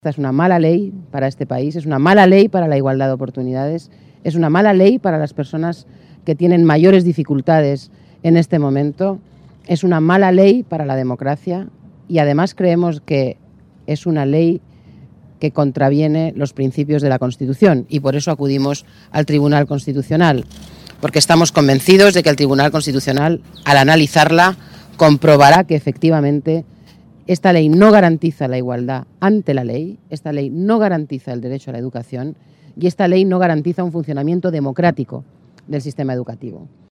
Declaraciones de Elena Valenciano tras recurrir la ley Wert en el Tribunal Constitucional 7/03/2014